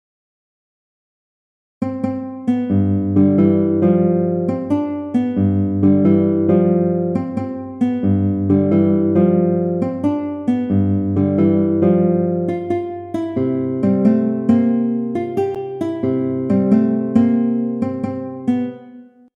Für Gitarre solo
Pop/Rock/Elektronik
Gitarre (1)
Hierbei handelt es sich um einen Blues.